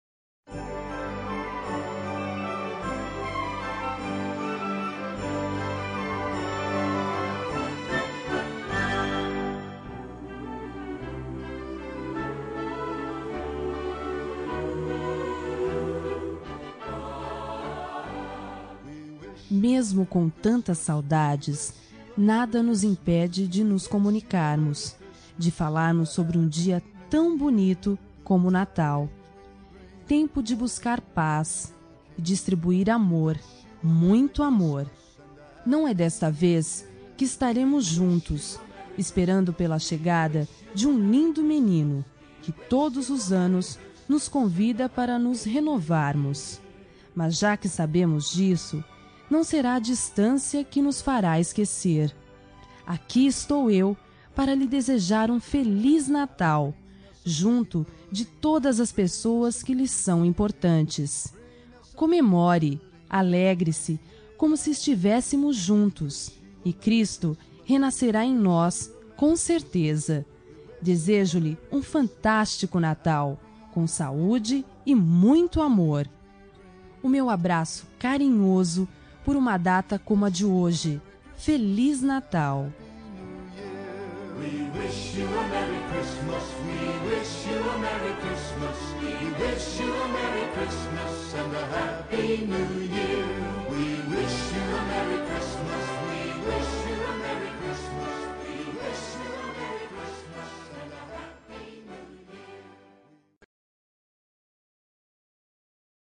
Natal Pessoa Especial – Voz Feminina – Cód: 348233 – Distante